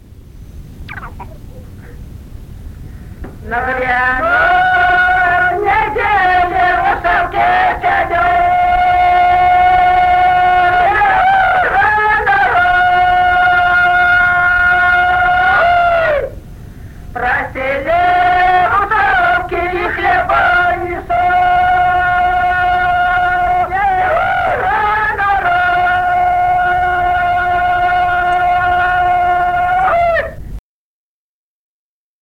Народные песни Стародубского района «На гряной неделе», гряная.
1963 г., с. Курковичи.